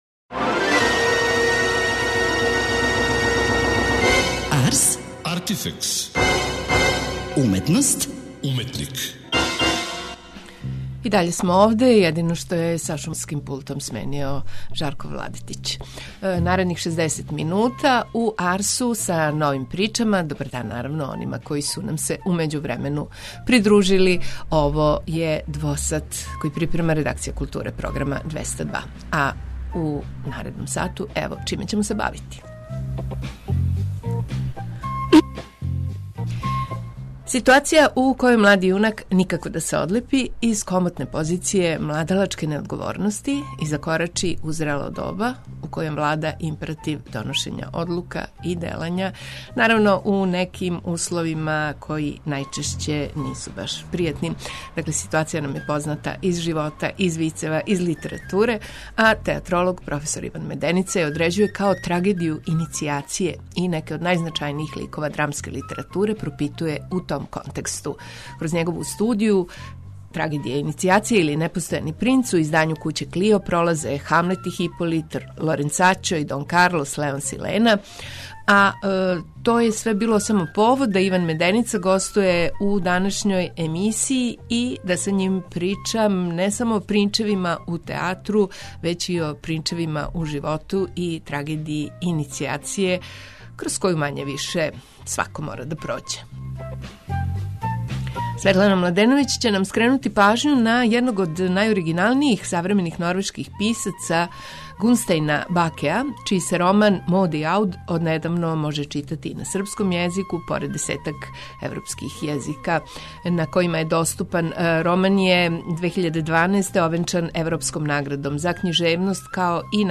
Емисију